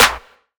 Clap (TI).wav